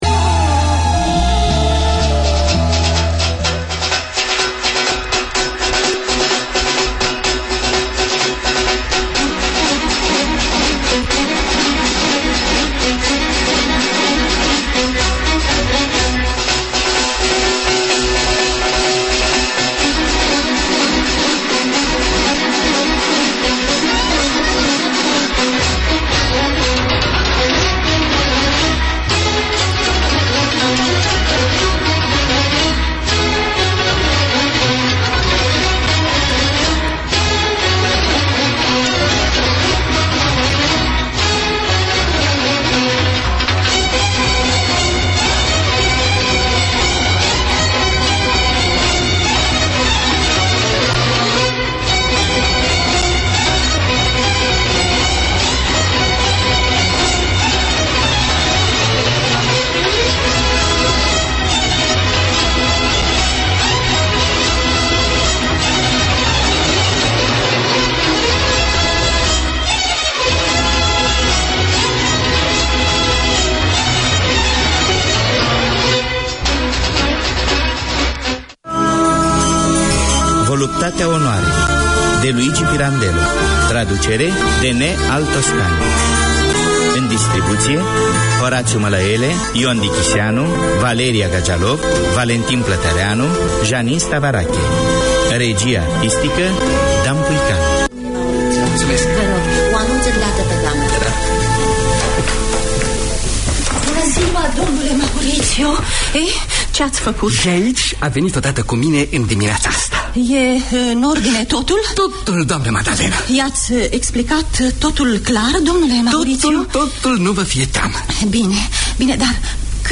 This 60 minute show presents news from Romania and from the Romanian community living in Auckland. The show introduces local and touring personalities, arts and sports news and children’s segments, shares humour and favourite Romanian music and takes live calls from listeners.